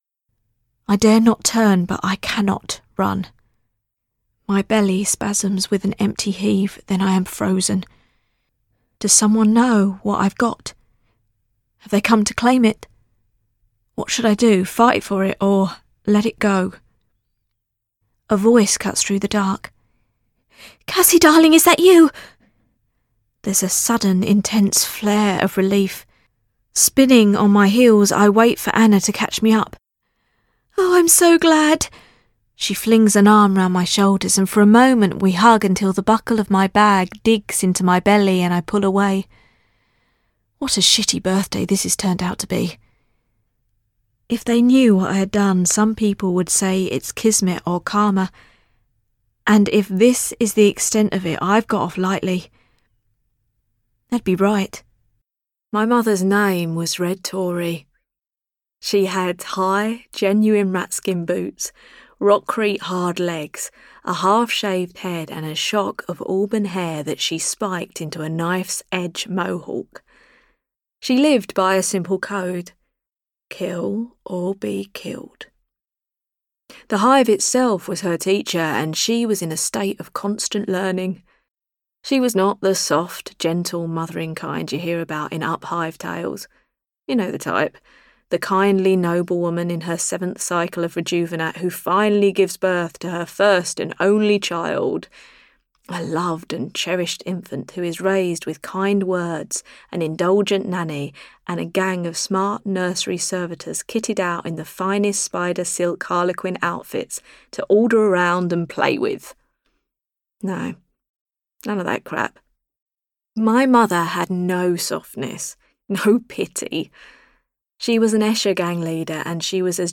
• Native Accent: London
• Home Studio